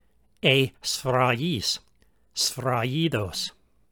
Pronunciation Note: When a single Gamma (γ) is followed by the vowels Epsilon (ε) or Iota (ι), or by the digraph Epsilon-Iota (ει) as in the word σφραγίς, the Gamma is pronounced like the y in yet.